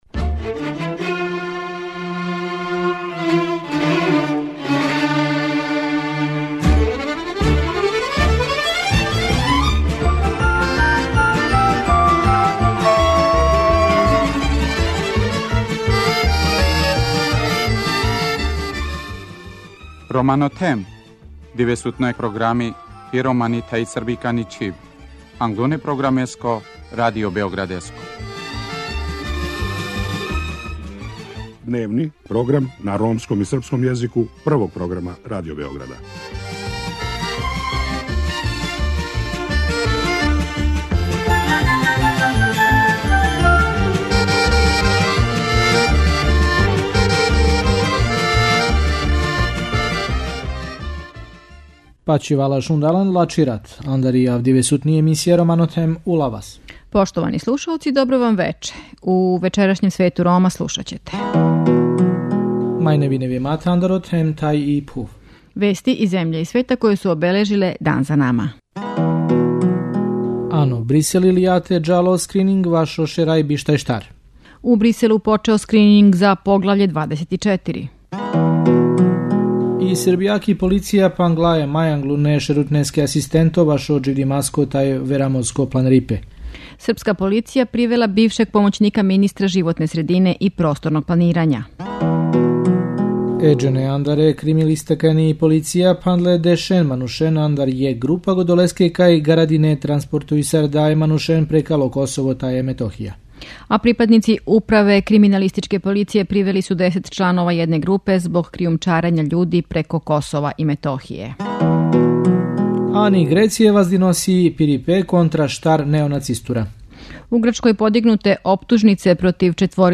У другом делу емисије припремили смо репортажу о Ромима у Ираку, који су као и у другим земљама, суочени са дискриминацијом и узнемиравањем.